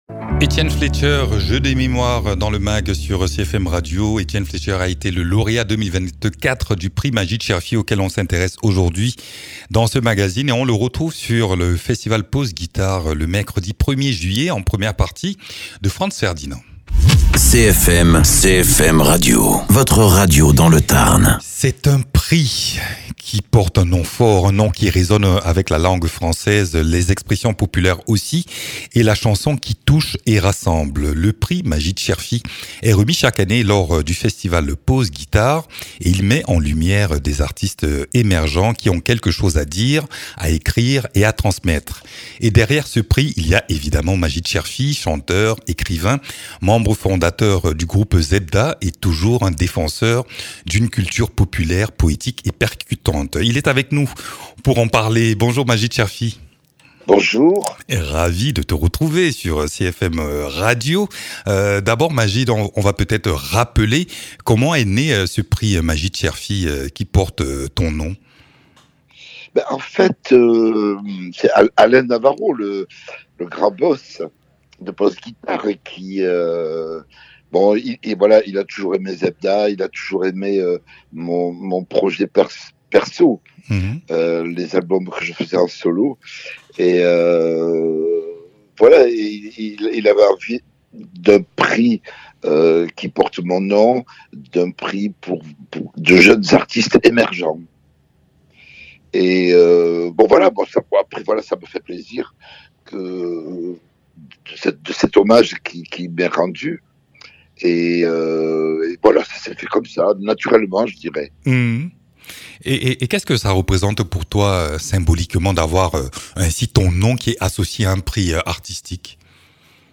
Invité(s) : Magyd Cherfi, écrivain, chanteur et auteur.